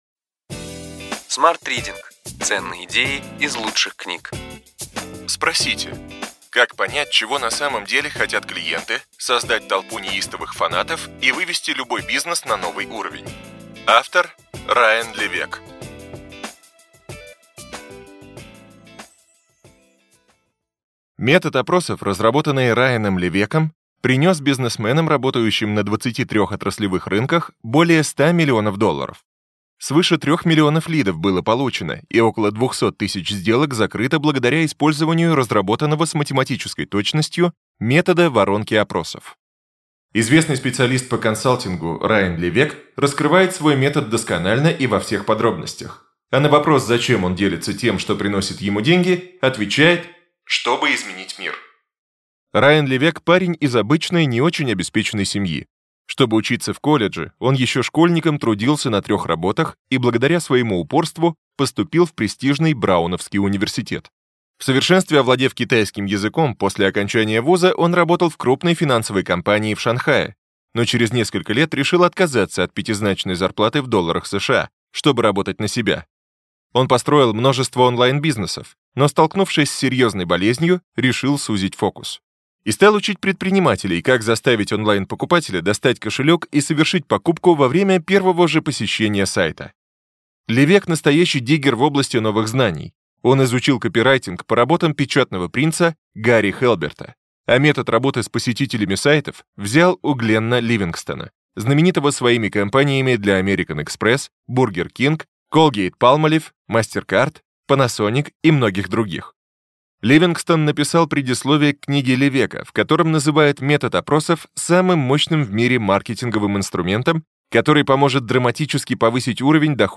Аудиокнига Ключевые идеи книги: Спросите. Как понять, чего на самом деле хотят клиенты, создать толпу неистовых фанатов и вывести любой бизнес на новый уровень.